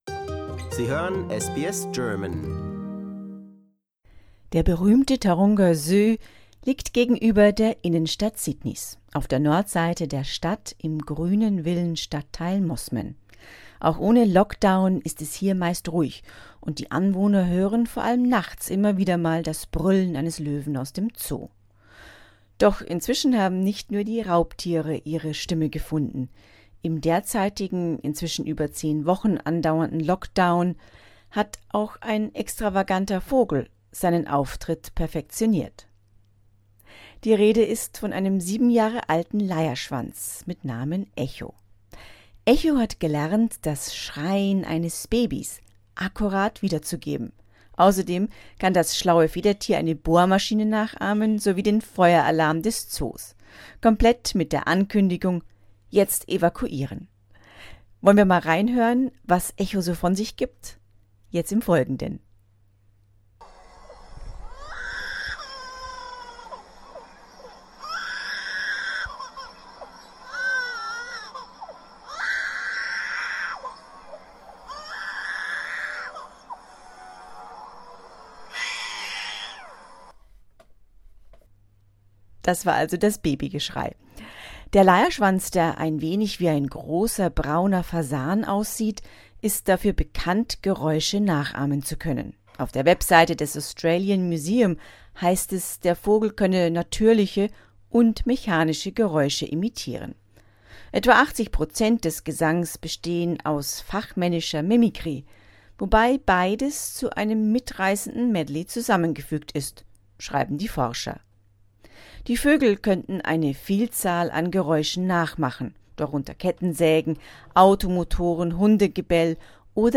Ohrenbetäubend: Leierschwanz imitiert schreiendes Baby